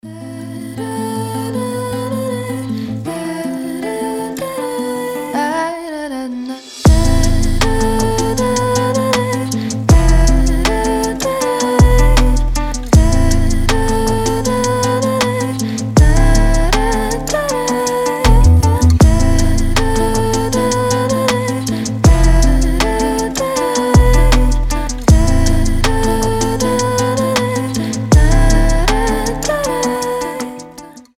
мелодичные
красивый женский голос
расслабляющие
Проникающая в душу красивая музыка